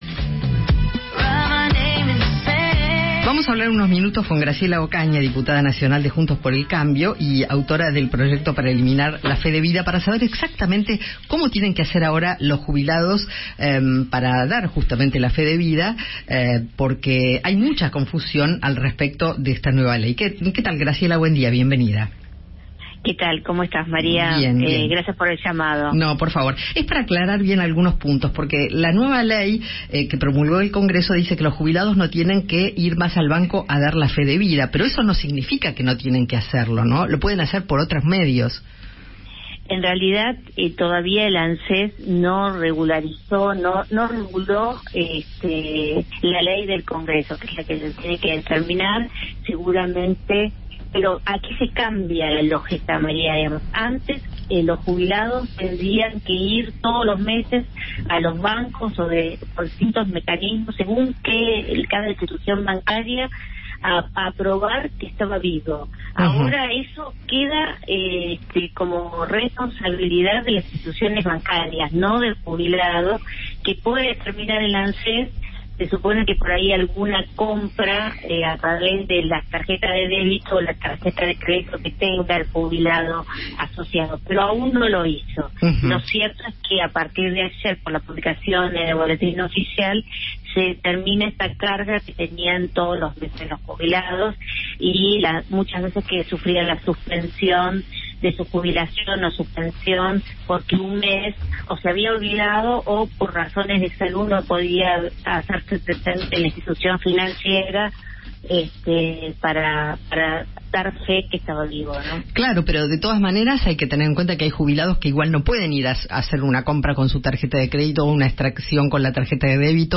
Graciela Ocaña, precandidata a Legisladora por Juntos por el Cambio, conversó con Eduardo Feinmann sobre la oficialización de la ley que suprime la obligatoriedad del certificado de supervivencia para cobrar la jubilación.